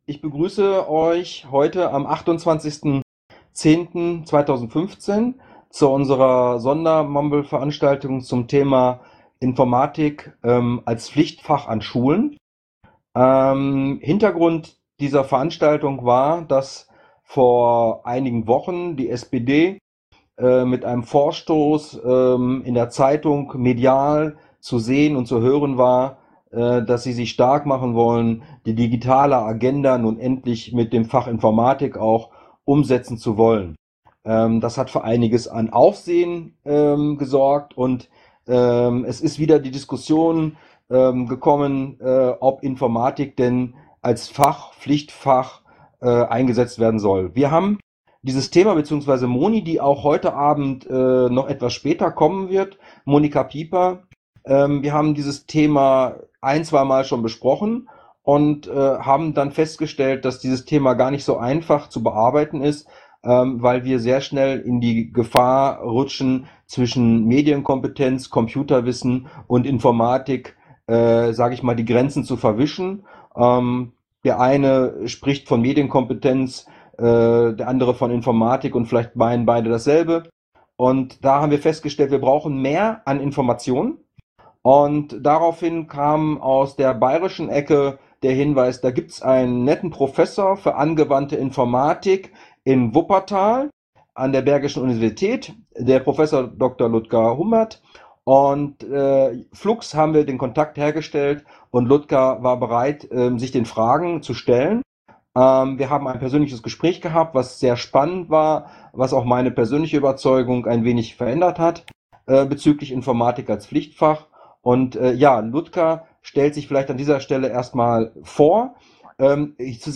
An der Diskussionsveranstaltung waren folgende Podiums-Teilnehmer beteiligt: